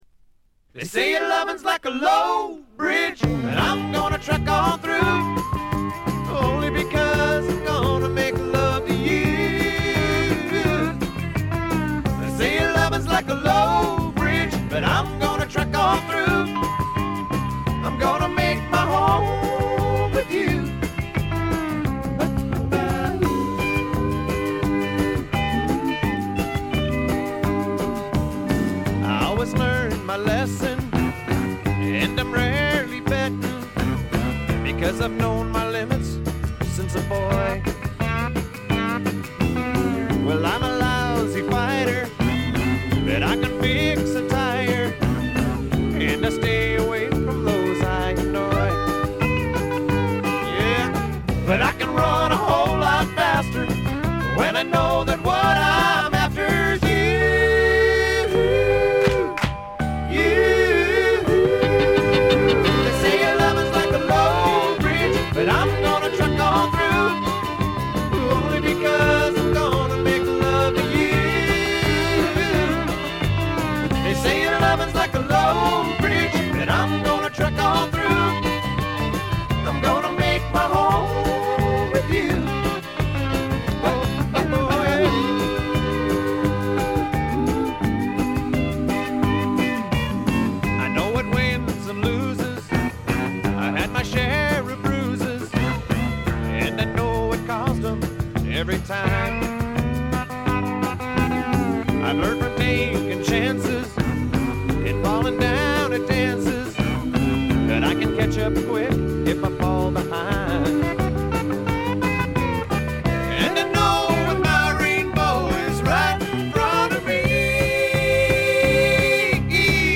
部分試聴ですがほとんどノイズ感無し。
試聴曲は現品からの取り込み音源です。
Banjo, Harp
Fiddle
Steel Guitar